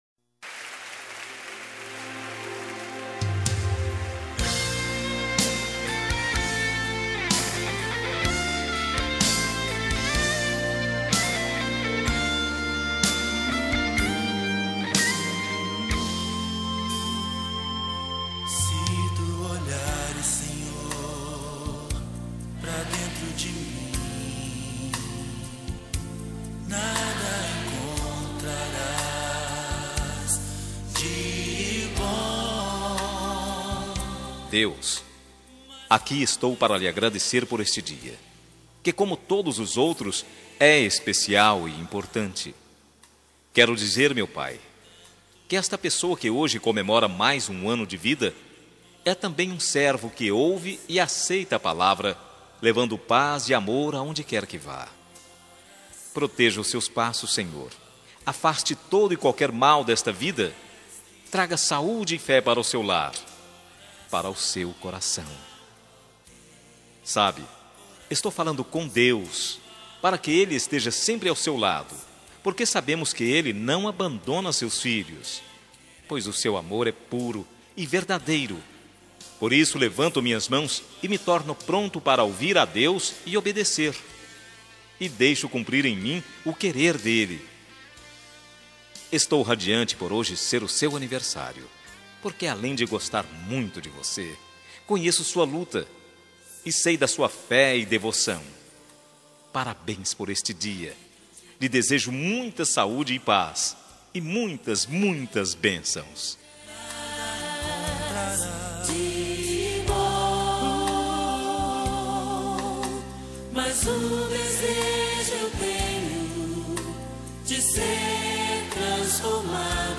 Aniversário Pessoa Especial Gospel – Voz Masculina – Cód: 6048